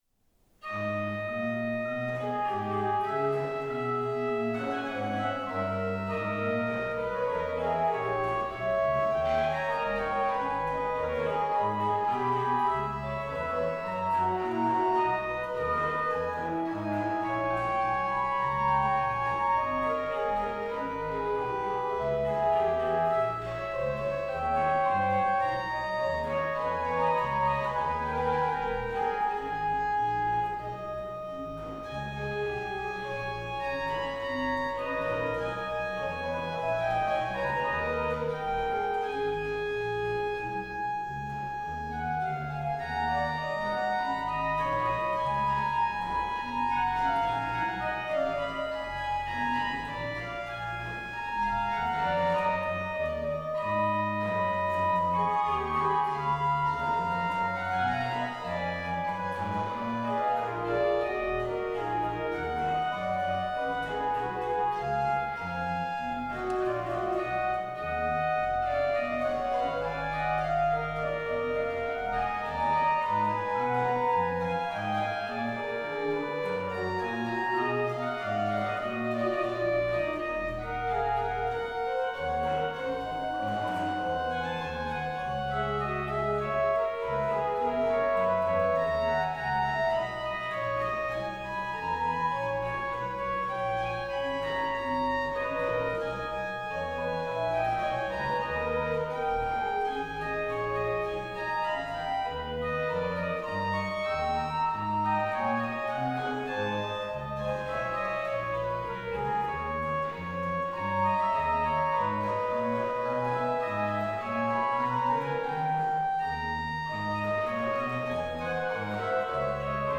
Registration   rh: HW: Sal4, Rfl4 (8ve lower)
lh: BW: Fl4, Gms4 (8ve lower)
Ped: Sub16, Por8